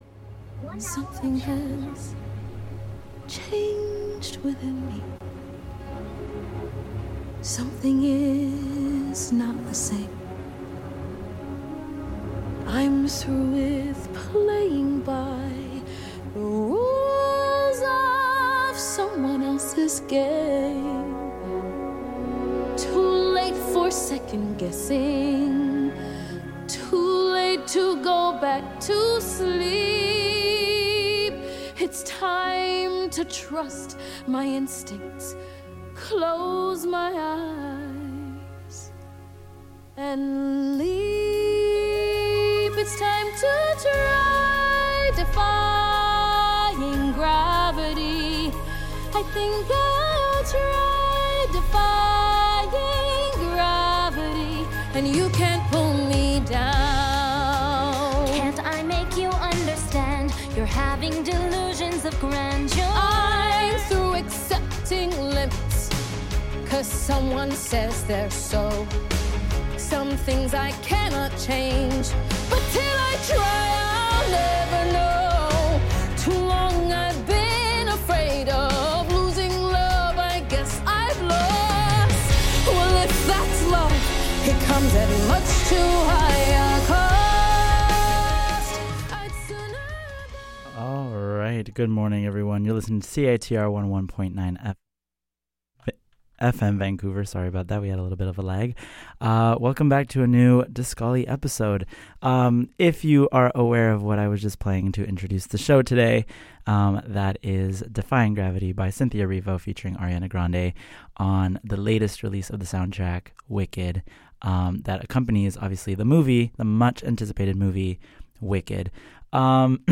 Moving onto the discovered artist of the week, Discollie hosts an On-Air interview with Calgary-based band 'Emblematics' and their most recent album 'Removal' with an accompanying tour around Canada!